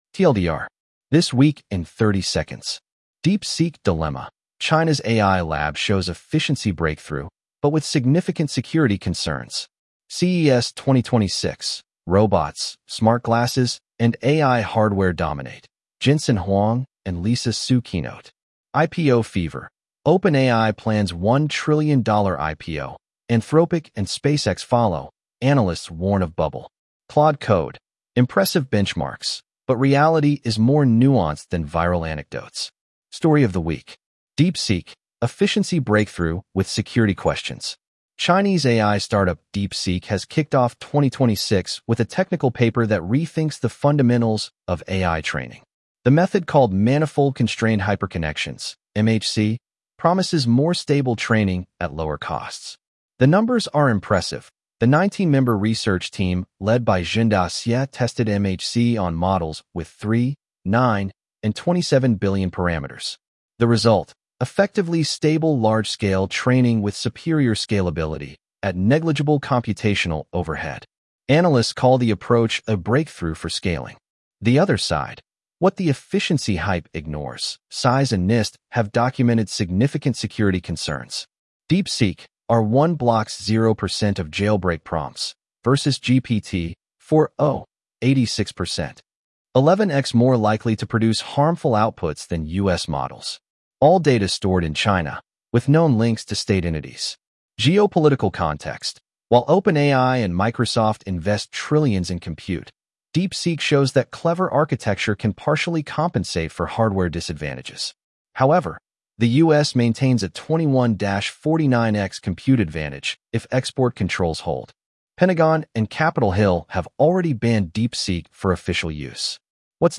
Read aloud with edge-tts (Microsoft Azure Neural Voice: en-US-AndrewNeural)